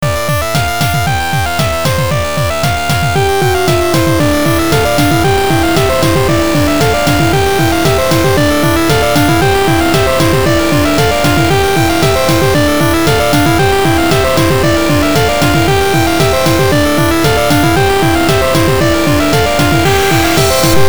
Old Chiptune (Two Versions)
An old chiptune I made back when I was using Pixel's "Org maker"There's two versions, a scratchy version, anbd a more siney smooth one...